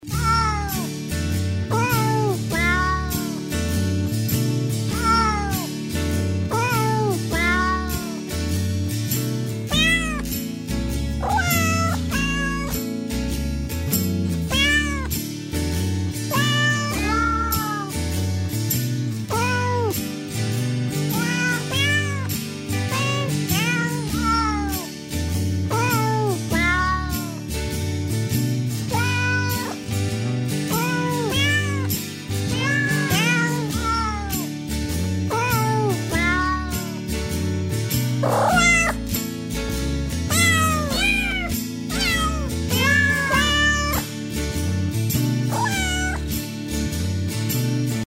мяуканье
кот